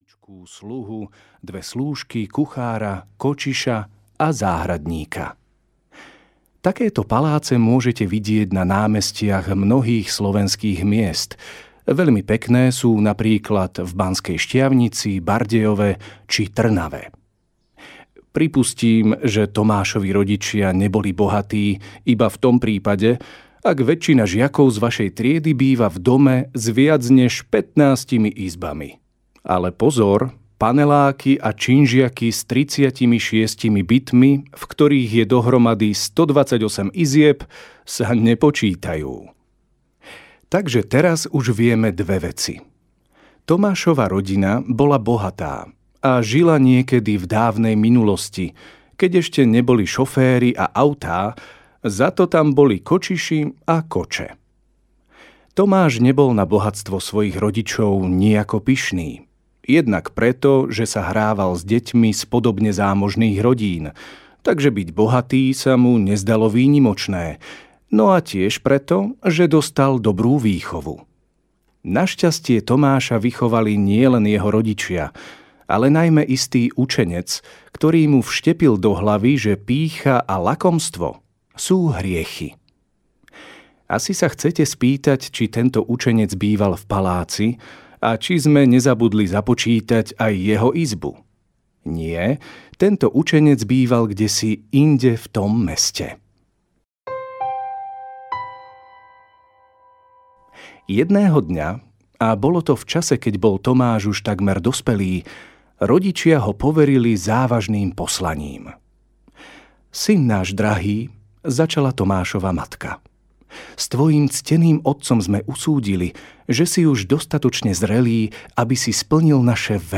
Nájdi svätého audiokniha
Ukázka z knihy
najdi-svateho-audiokniha